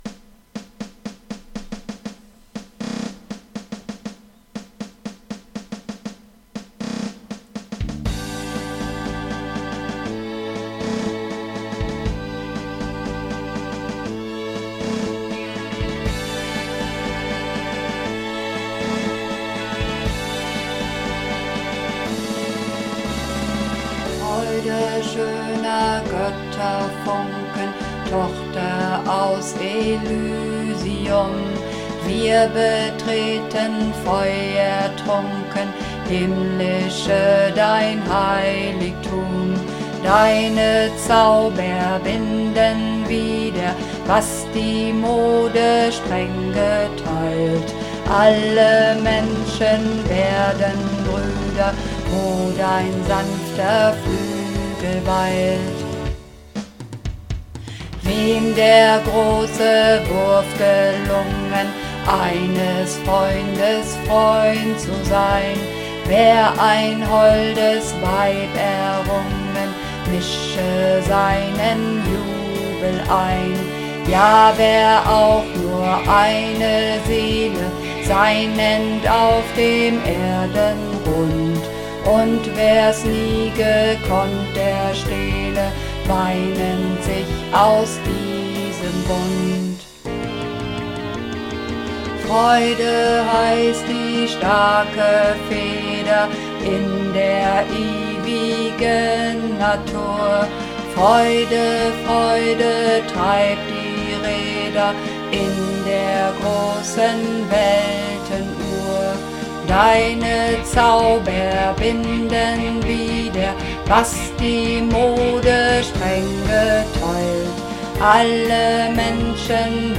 Runterladen (Mit rechter Maustaste anklicken, Menübefehl auswählen)   Ode an die Freude (Bass)
Ode_an_die_Freude__2_Bass.mp3